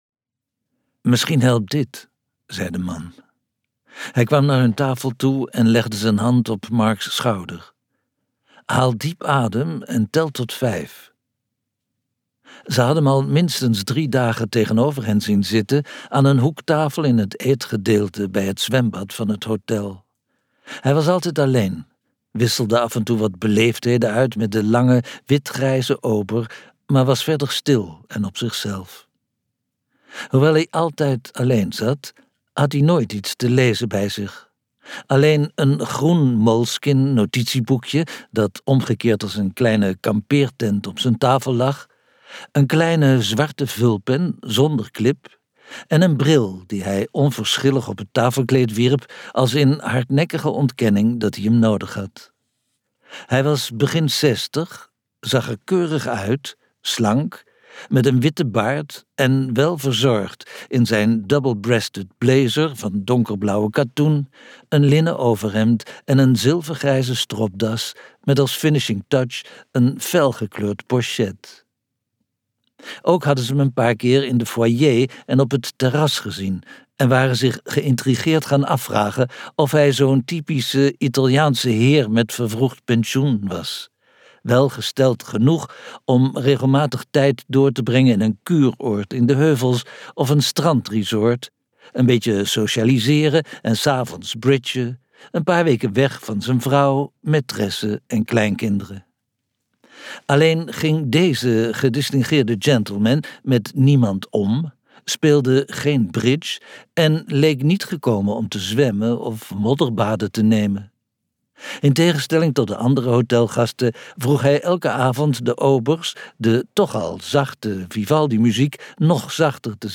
Ambo|Anthos uitgevers - De gentleman uit peru luisterboek